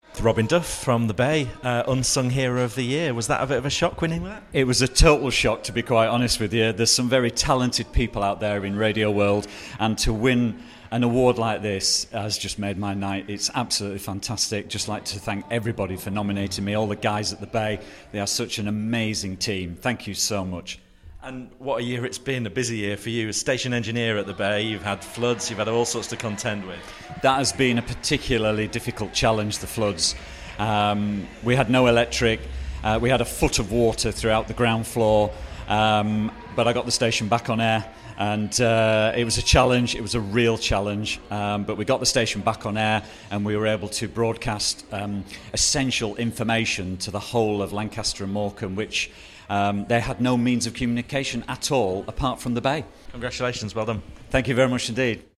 at the Arqiva Commercial Radio Awards 2016.